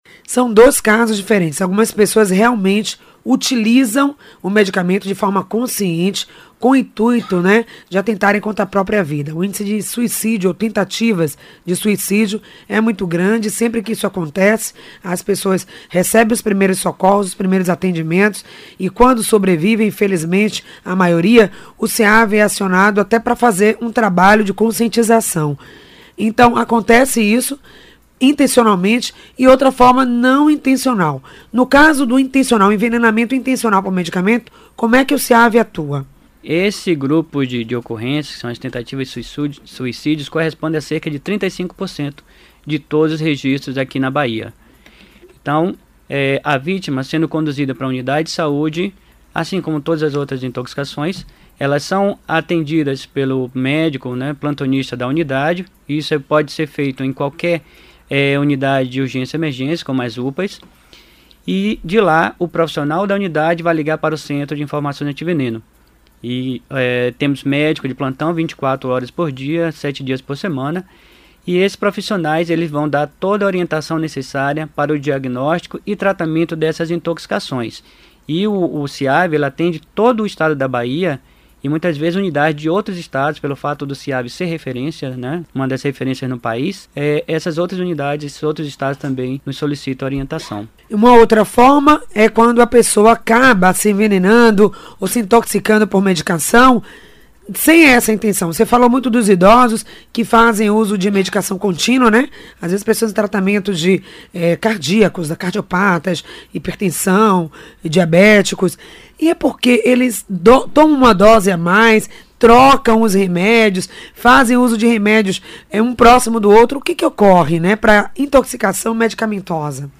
Intoxicação medicamentosa é tema de entrevista do Ciave na Rádio Excelsior
POD-CAST-INTOXICAÇÃO-DE-MEDICAMENTOS.mp3